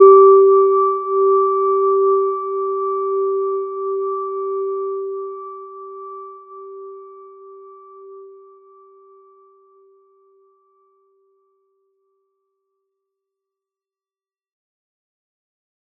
Gentle-Metallic-1-G4-mf.wav